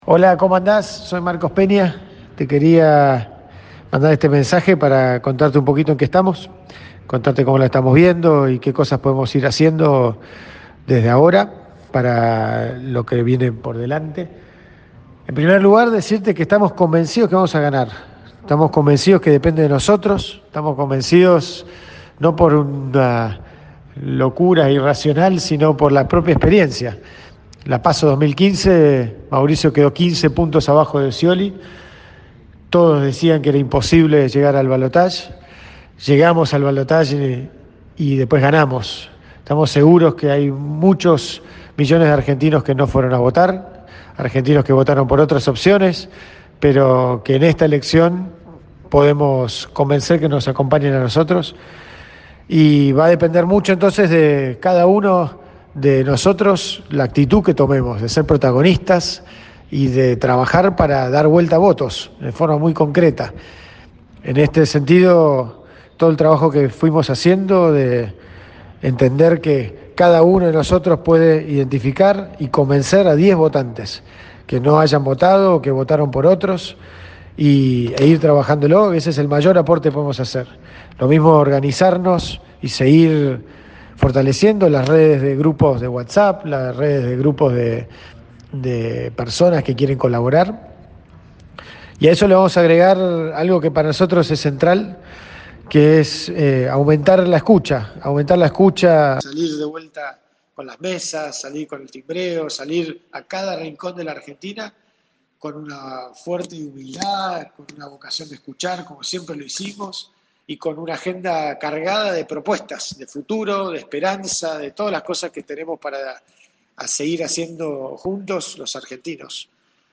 En un audio difundido esta tarde entre fiscales, militantes y voluntarios, Peña les aseguró que "hay millones de argentinos que no fueron a votar" y dijo que hay "argentinos que votaron por otras opciones, pero que en esta elección" pueden "convencer para que los acompañen".
El mensaje de Marcos Peña